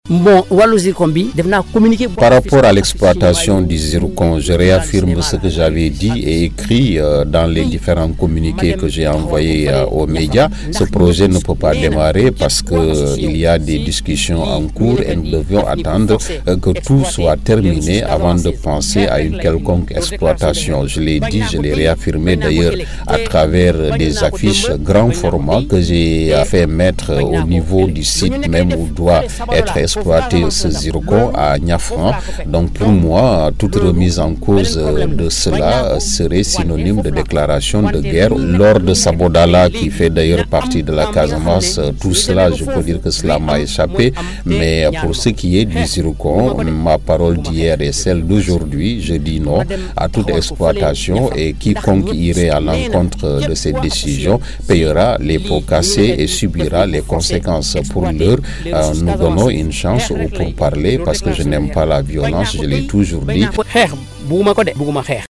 qui a interviewé Salif Sadio un des chefs du Mouvement Séparatiste de la Casamance (MFDC)